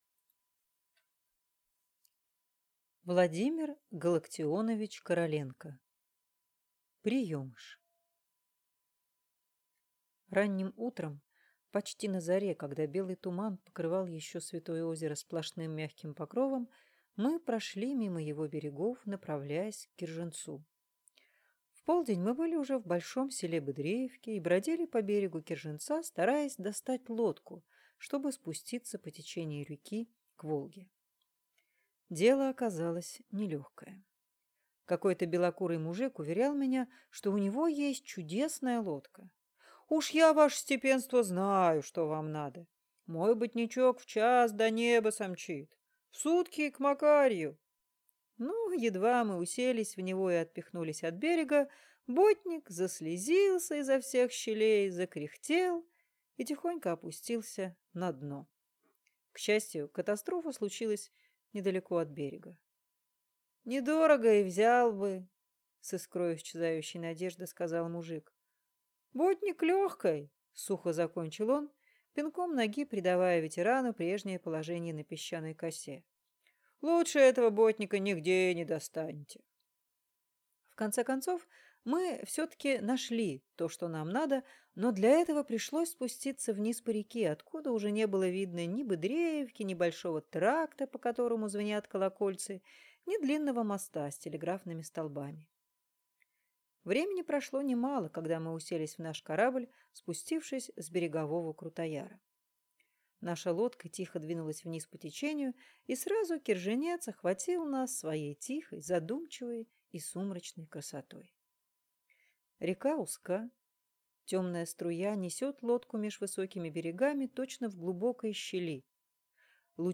Аудиокнига Приемыш | Библиотека аудиокниг